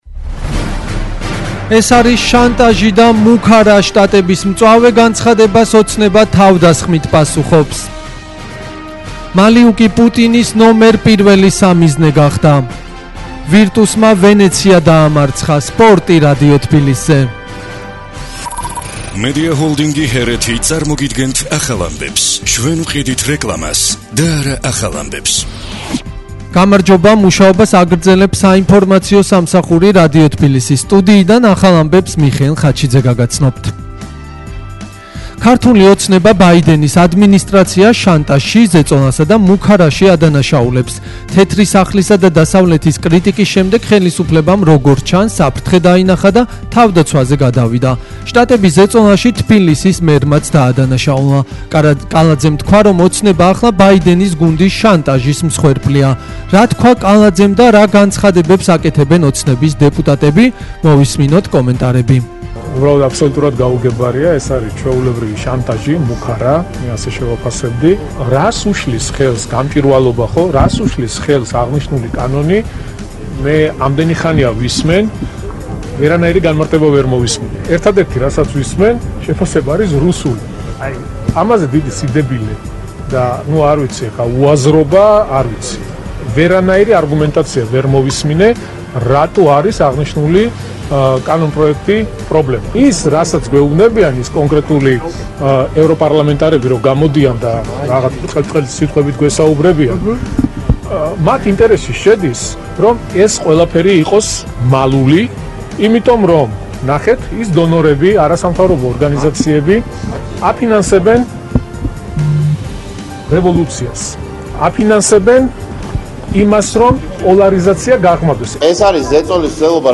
ახალი ამბები 14:00 საათზე